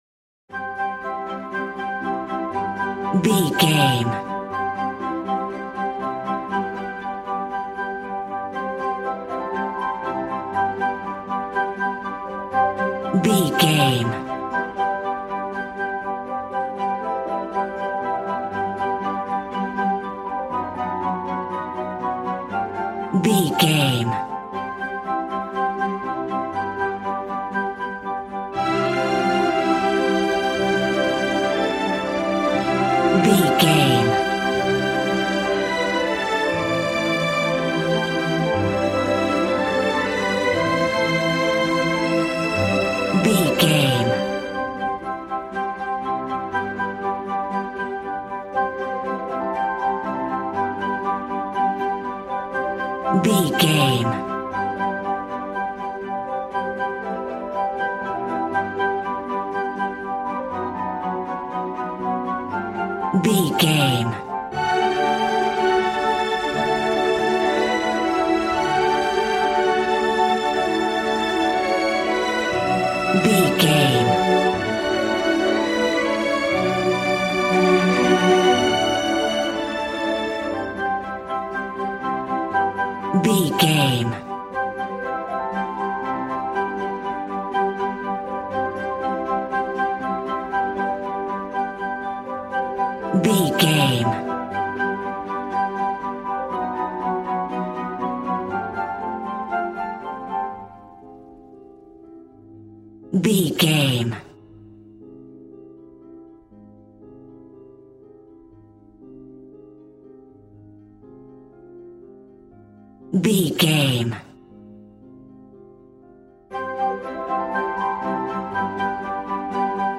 Regal and romantic, a classy piece of classical music.
Ionian/Major
regal
strings
violin
brass